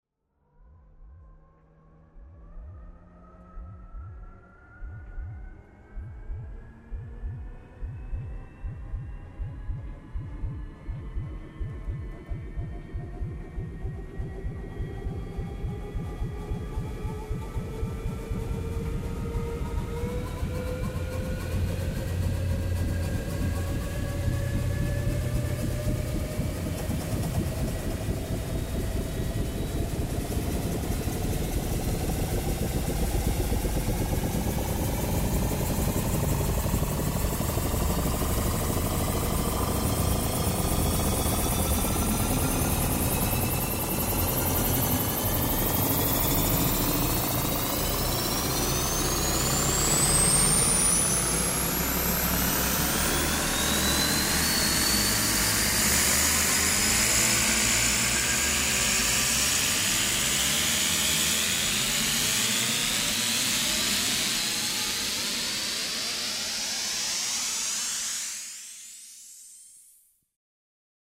Гул долгого запуска машины времени для переноса сущности человека в прошлое или будущее